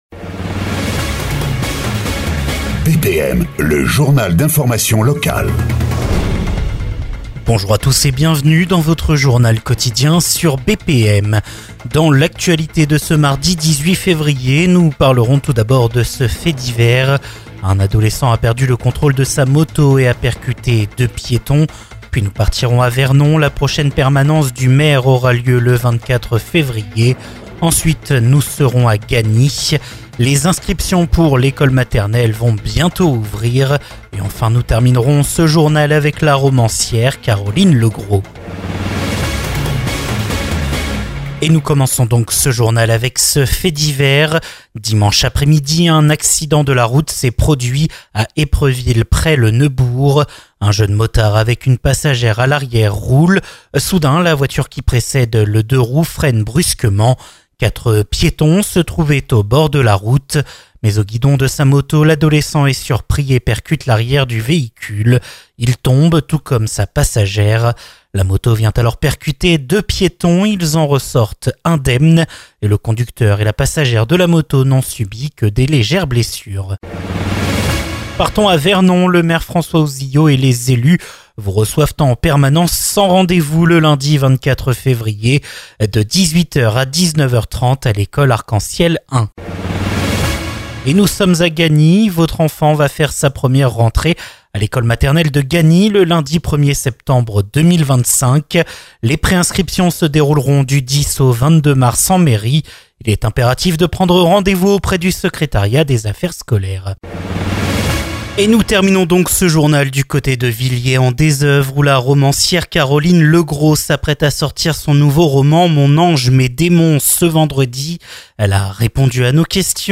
Source BPM, la radio de Vernon.